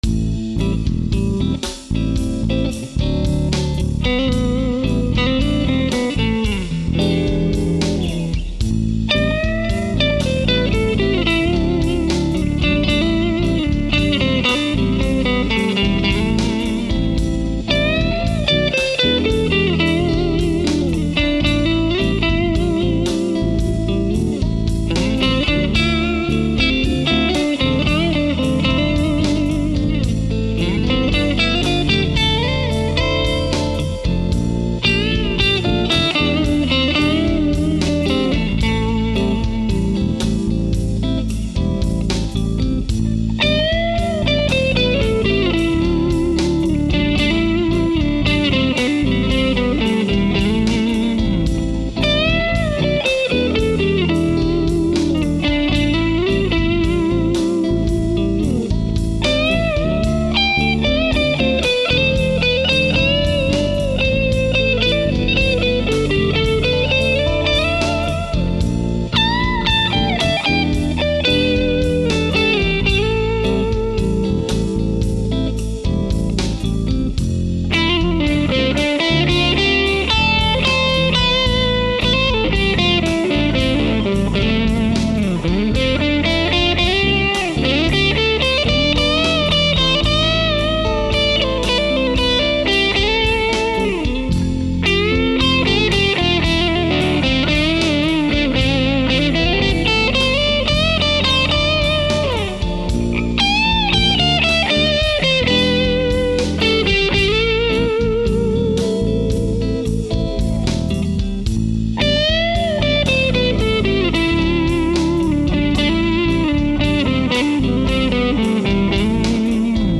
VRX22 (22 watts, 6V6 equipped), 1x12 VRX Extension Cabinet, Fender Strat with single coils, St.Guitar Company - Messenger guitar with humbuckers
Rhythm: VRX22 Ch. 1, Fender Strat, no pedal effects
Lead: VRX22 Ch. 1, Messenger guitar, no pedal effects
PRX150-Pro set near maximum attenuation (conversation level)
"All guitar parts were recorded in my home studio, at conversation levels, with a Nady RSM-2 Ribbon mic 2” from the grille cloth. The guitar tracks were all recorded raw with no EQ. The PRX150-Pro allowed me to record at a low volume level, late at night without disturbing my family or neighbors, all while maintaining my tone and dynamics. "